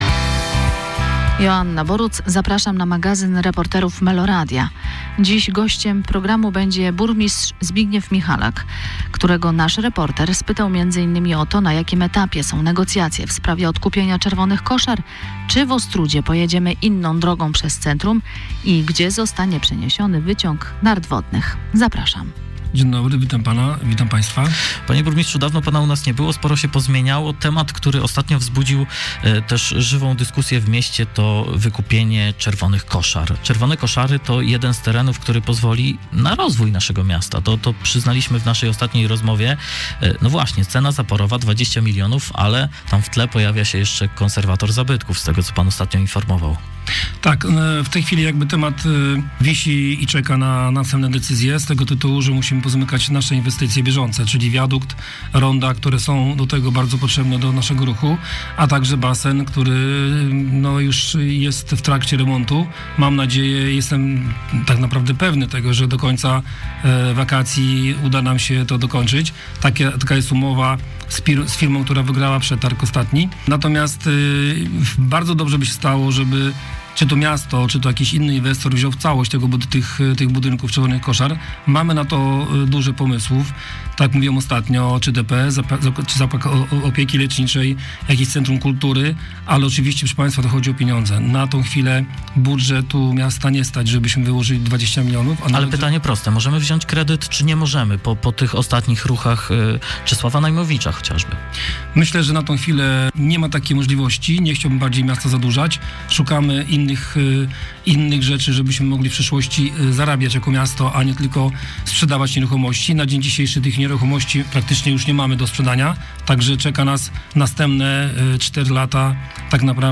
Burmistrz Ostródy Zbigniew Michalak w wywiadzie z reporterem Meloradio